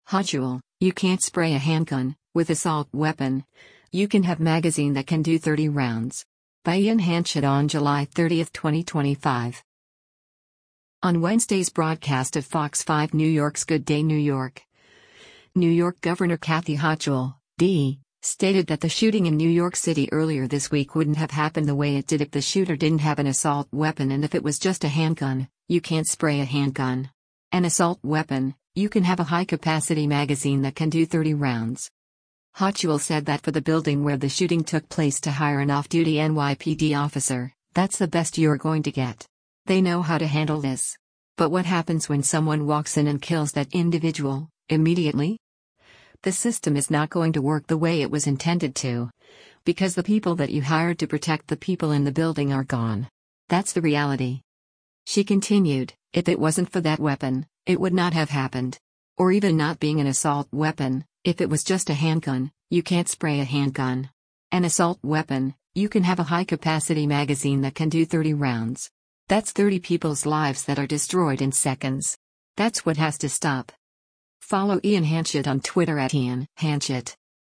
On Wednesday’s broadcast of Fox 5 New York’s “Good Day New York,” New York Gov. Kathy Hochul (D) stated that the shooting in New York City earlier this week wouldn’t have happened the way it did if the shooter didn’t have an assault weapon and “if it was just a handgun, you can’t spray a handgun. An assault weapon, you can have a high-capacity magazine that can do 30 rounds.”